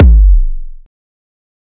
EDM Kick 25.wav